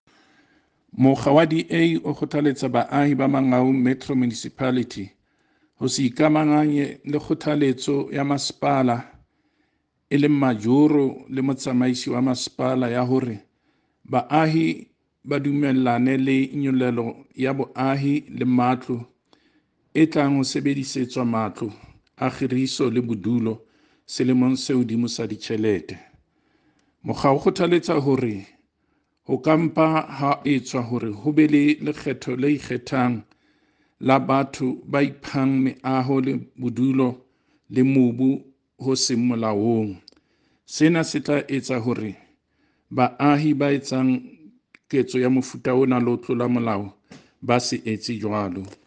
Sesotho by Cllr David Masoeu.